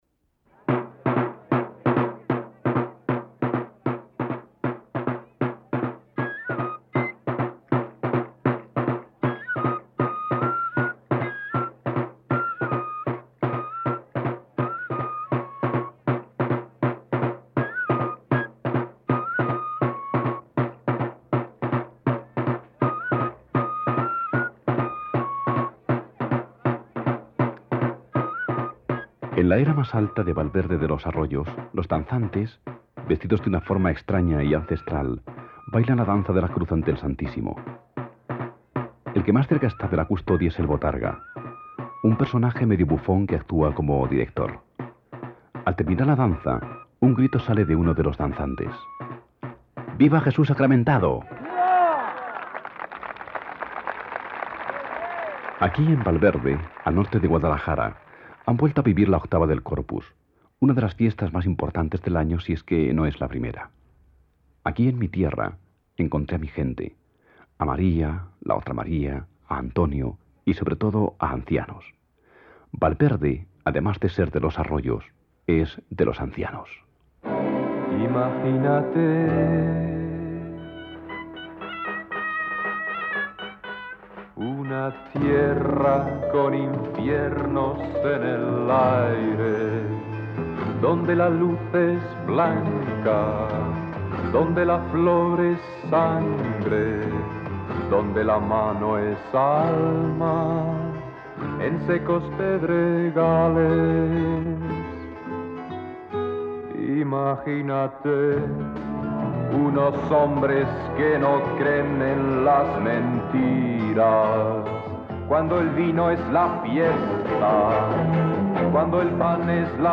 Raíces del Liberal. El programa de R.N.E. “Mi tierra, mi Gente” recogió así la Octava del Corpus del año 1986 en Valverde de los Arroyos